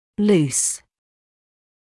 [luːs][луːс]свободный, неприкреплённый